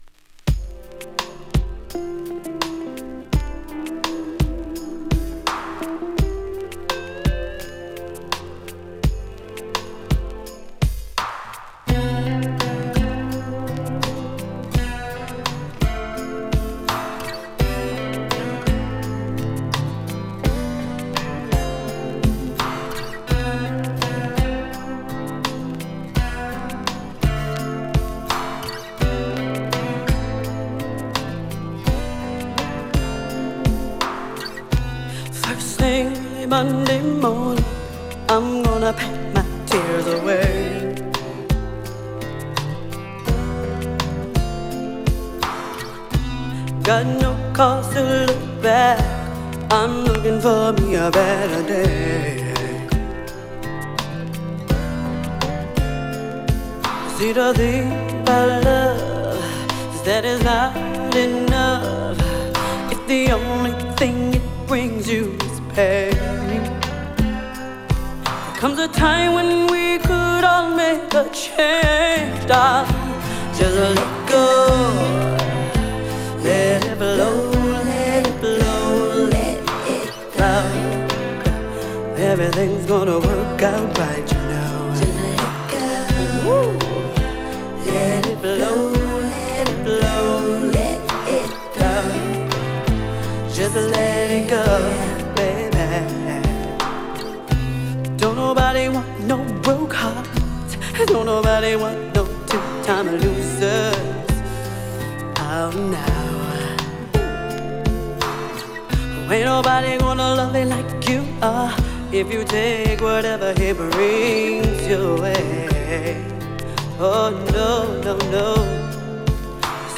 ヴァイナルでの流通量が極端に少ない傑作90's R&Bレアアイテム!